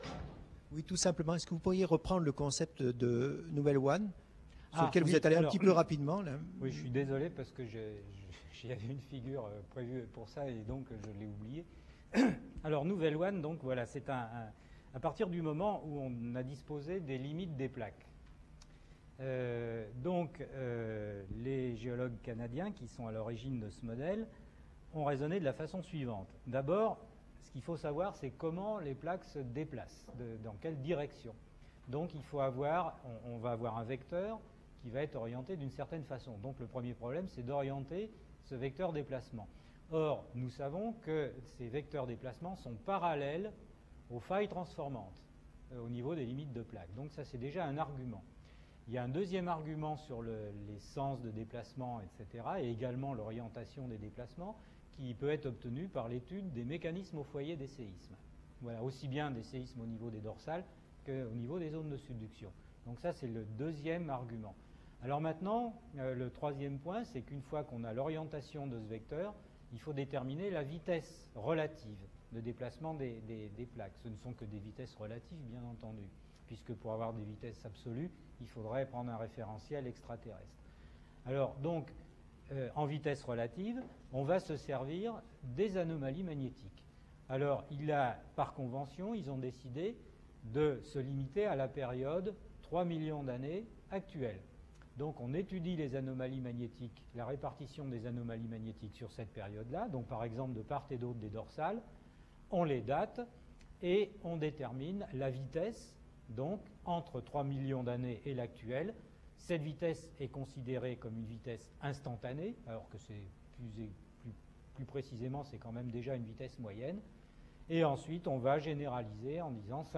Réponses à quelques questions posées par le public à l’issue de la conférence.